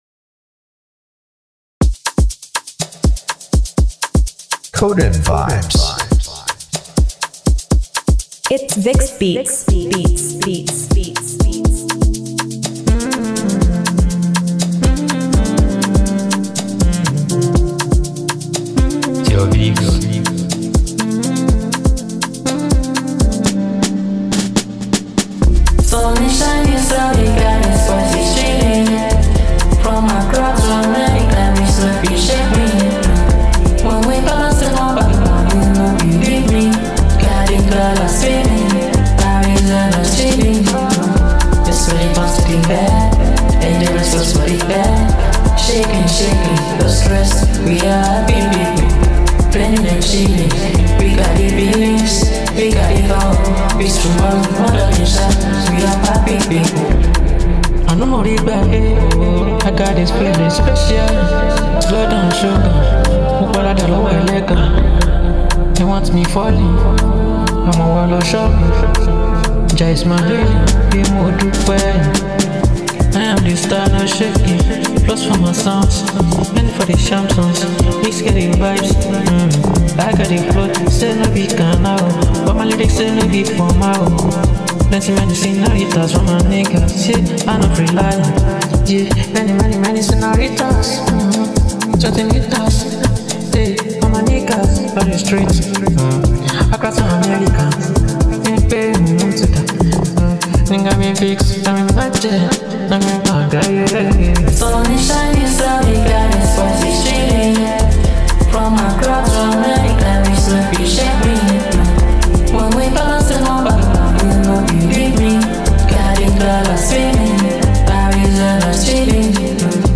soulful and rhythmic masterpiece